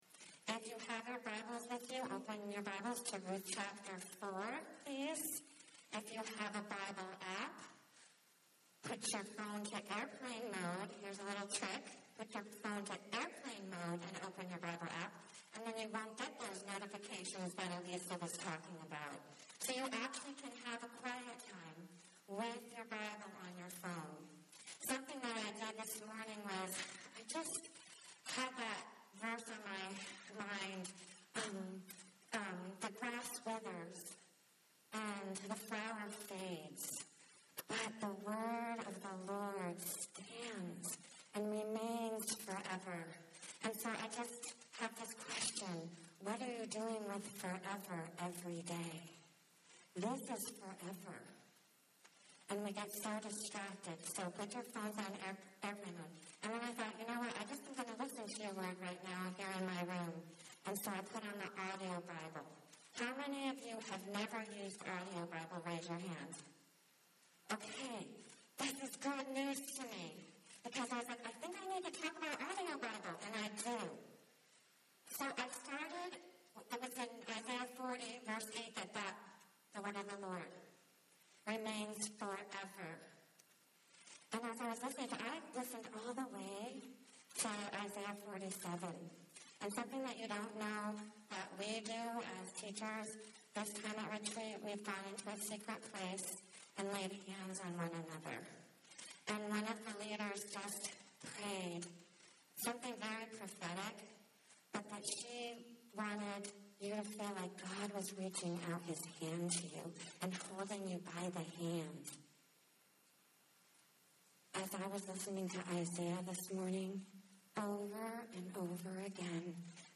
Women's Retreat 2019: Ruth A True Story